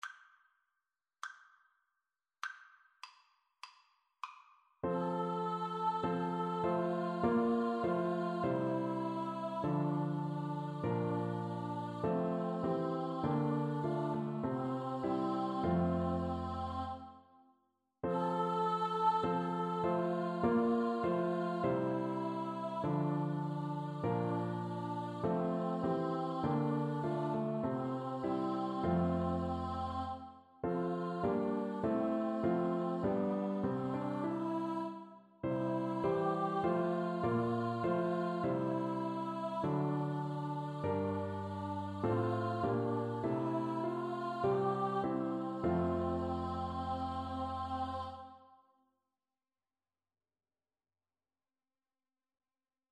Free Sheet music for Choir (SATB)
4/4 (View more 4/4 Music)
F major (Sounding Pitch) (View more F major Music for Choir )
Christmas (View more Christmas Choir Music)